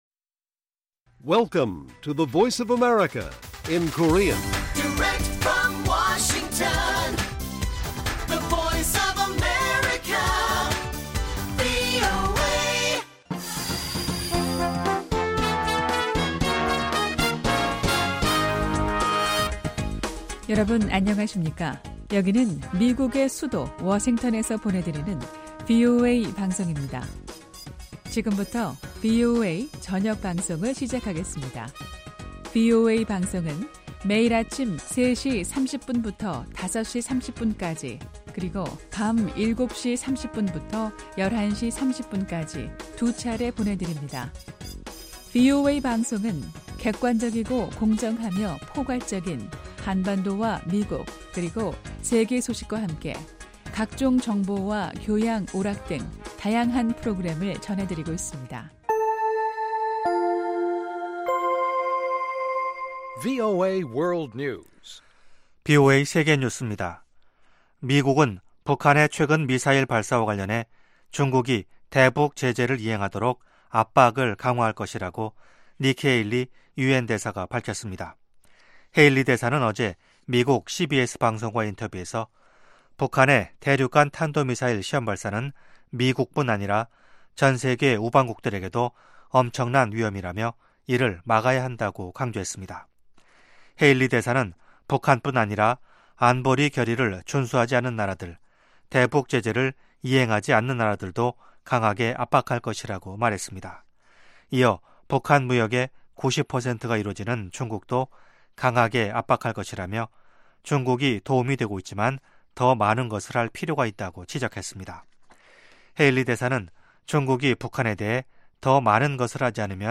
VOA 한국어 방송의 간판 뉴스 프로그램 '뉴스 투데이' 1부입니다. 한반도 시간 매일 오후 8:00 부터 9:00 까지, 평양시 오후 7:30 부터 8:30 까지 방송됩니다.